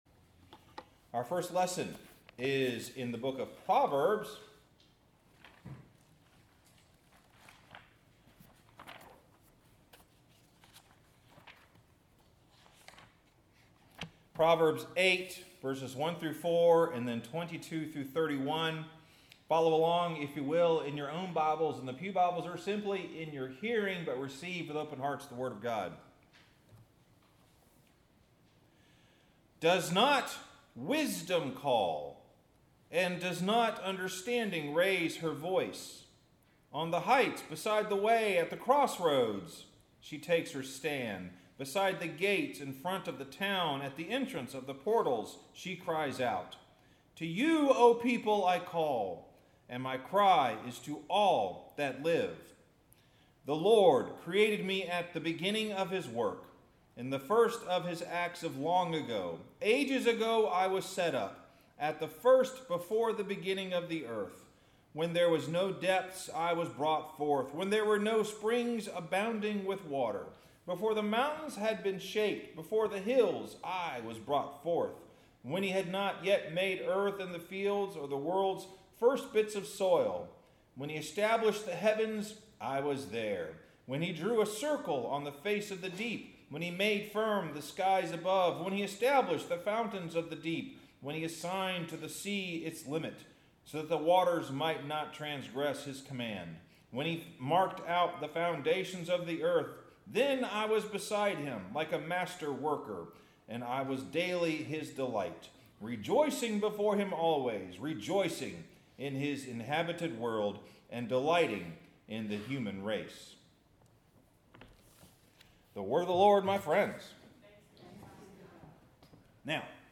Sermon – Earth Is More Expensive than Heaven